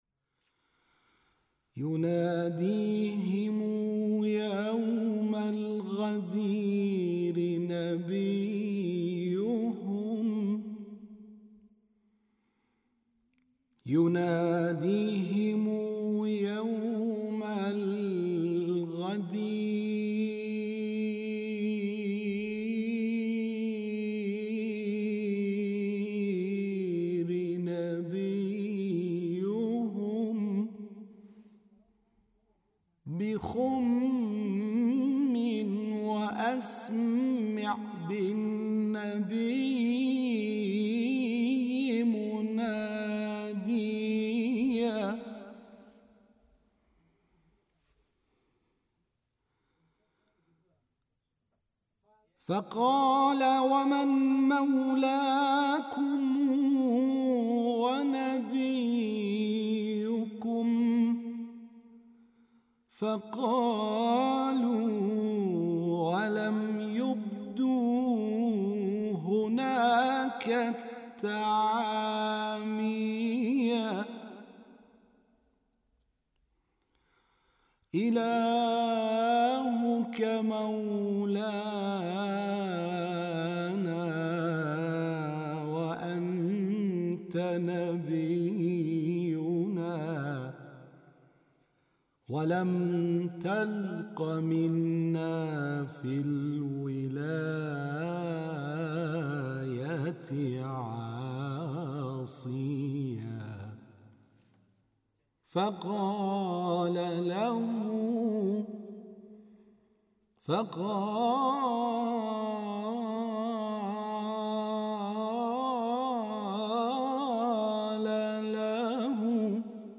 ابتهال خوانی